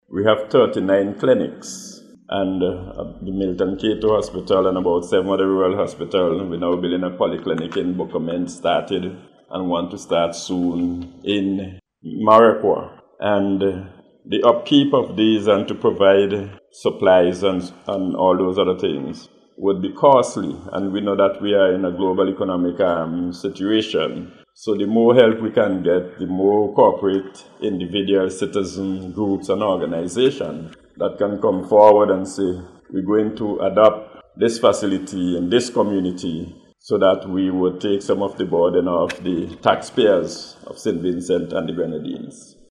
He was speaking at Monday’s handover a quantity of Medical Supplies from the Streams of Power Church at Sion Hill, valued at 40-thousand EC dollars, for use at the Milton Cato Memorial Hospital.